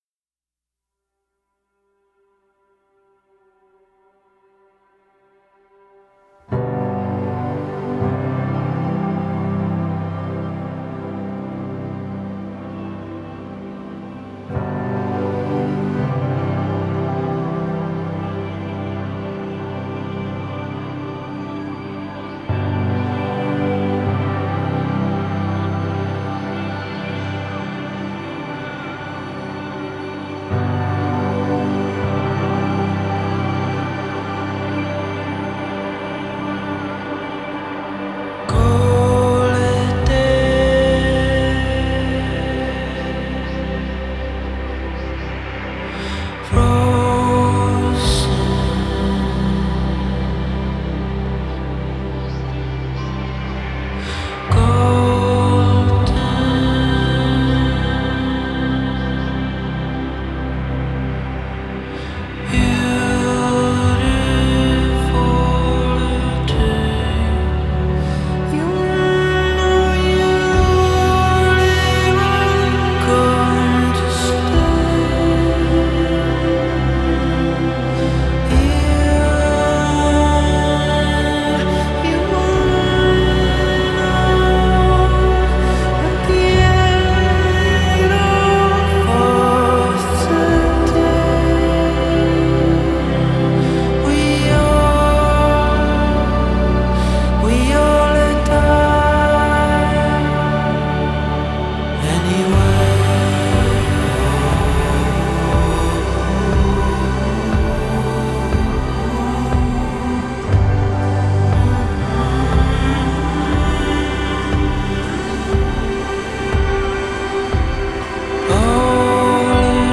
genre: post_rock